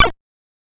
IceBallShoot.wav